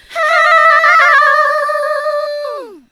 SCREAM10  -R.wav